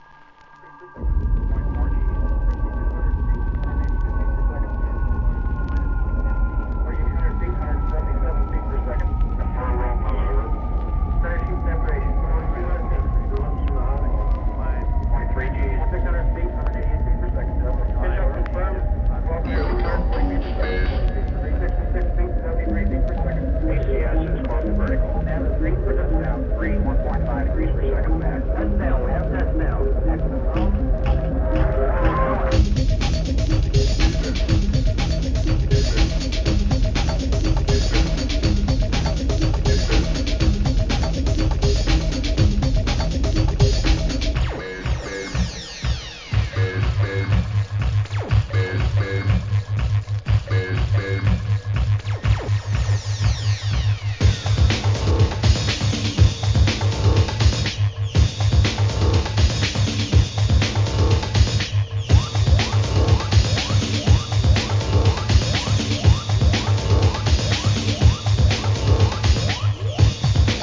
センスが光るサンプリング!!!